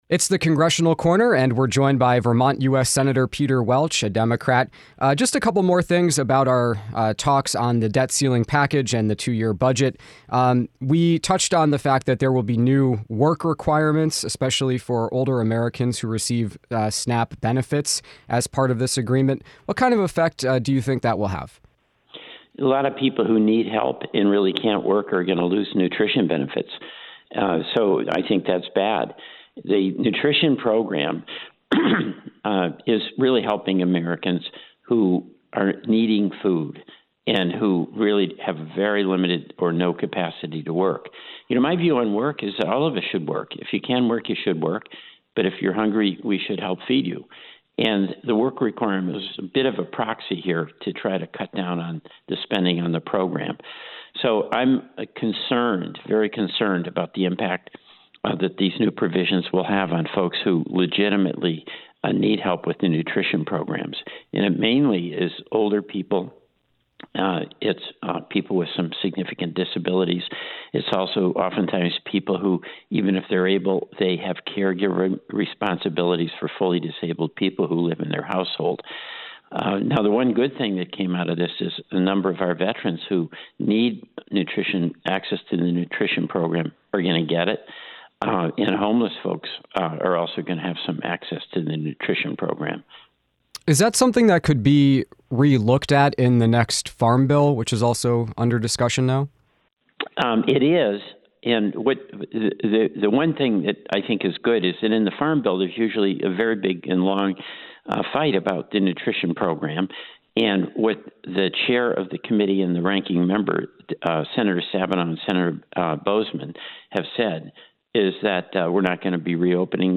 This interview was recorded June 6.